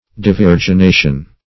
Search Result for " devirgination" : The Collaborative International Dictionary of English v.0.48: Devirgination \De*vir`gi*na"tion\, n. [L. devirginatio.]